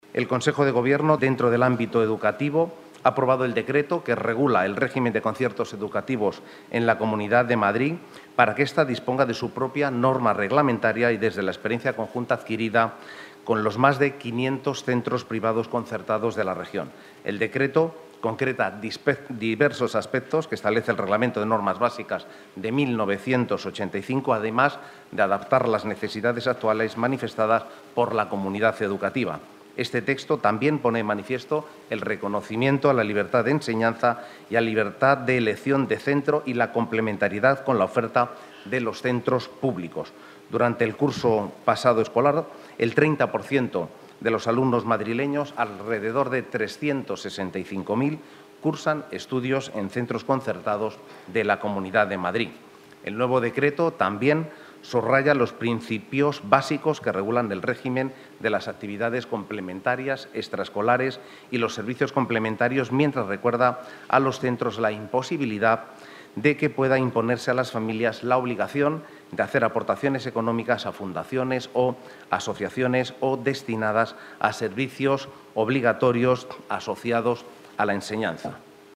Pedro Rollán en el Consejo de Gobierno